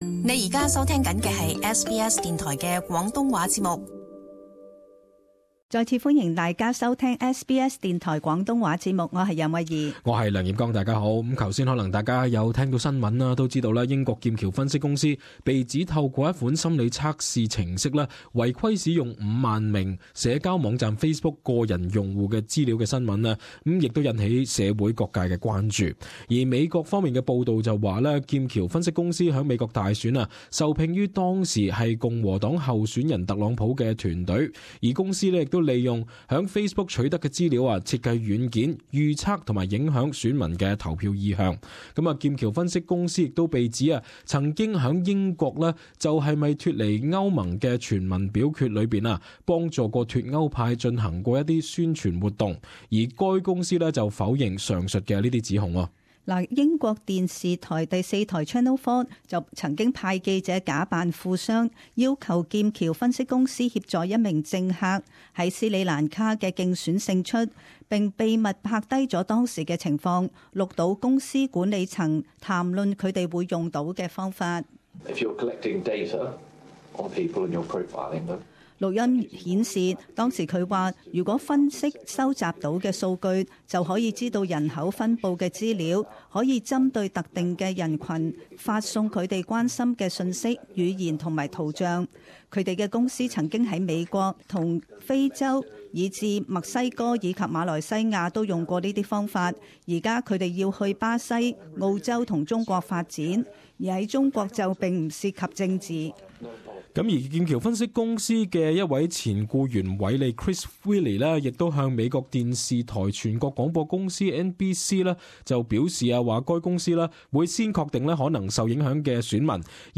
【時事報導】Facebook個人資料遭盜用